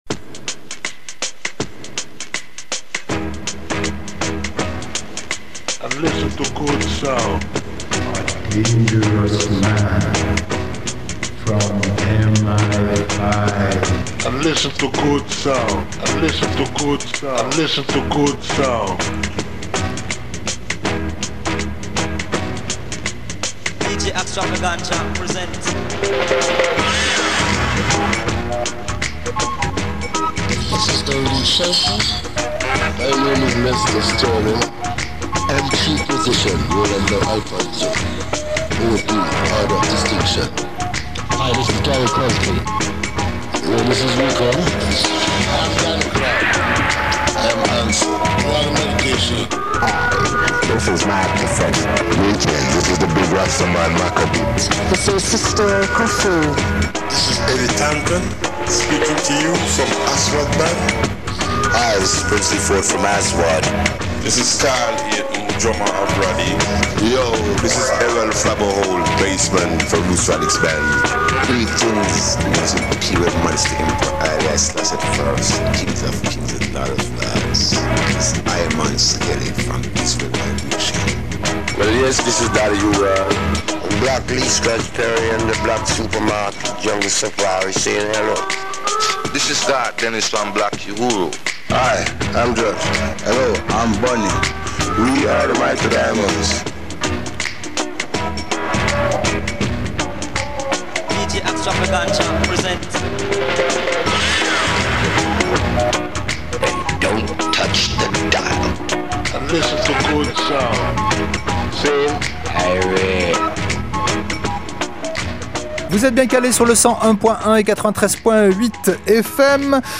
Black Super Market – radio show !
dub, salsa, funk, mestizo, ska, afrobeat, reggaeton, kompa, rumba, reggae, soul, cumbia, ragga, soca, merengue, Brésil, champeta, Balkans, latino rock…